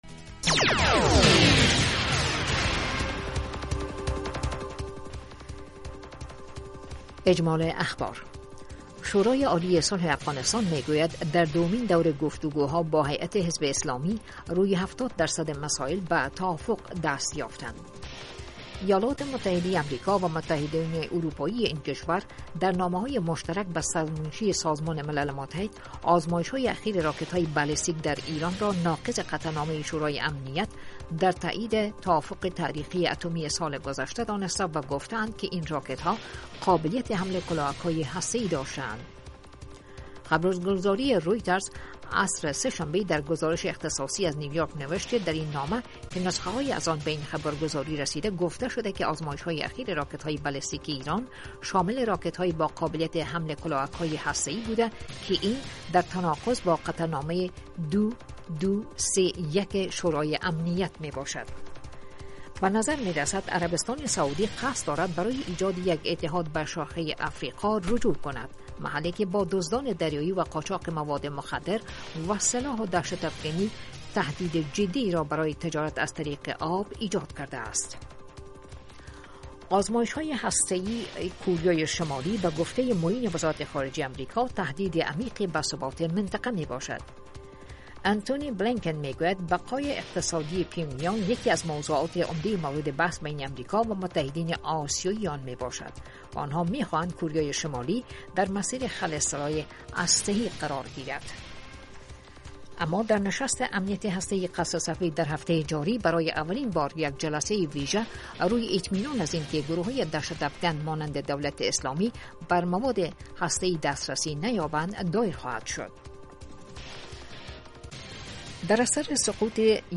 اخبار سه دقیقه ای صبحگاهی رادیو آشنای صدای امریکا